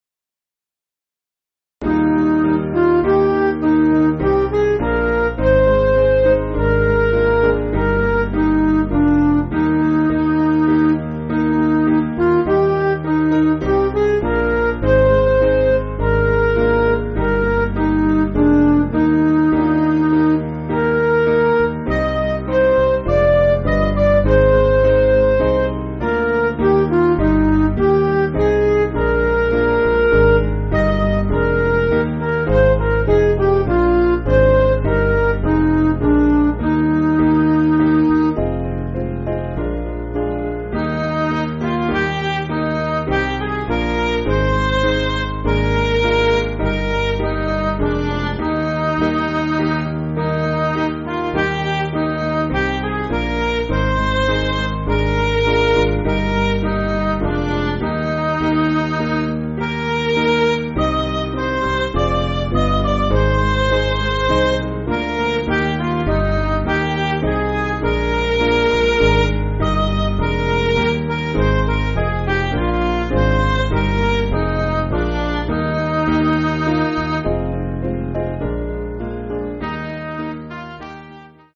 Piano & Instrumental
3/Eb
Midi